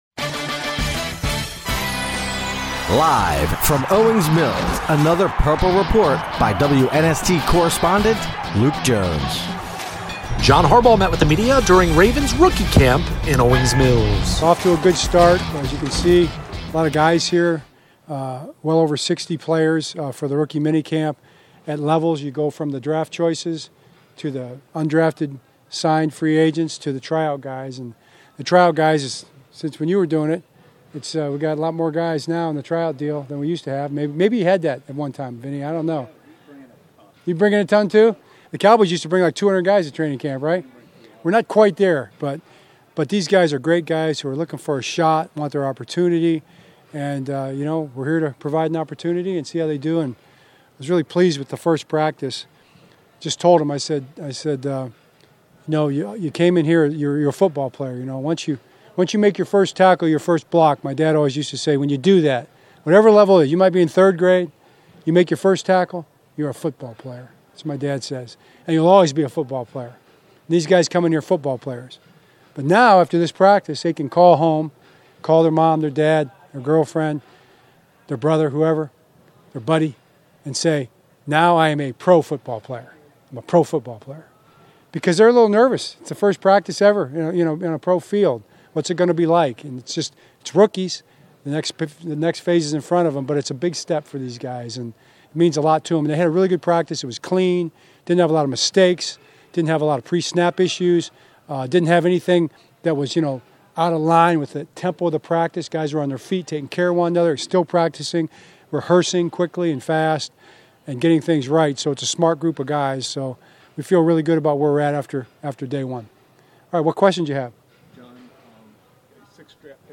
John Harbaugh discusses Joe Noteboom signing, other Ravens headlines from rookie camp
Locker Room Sound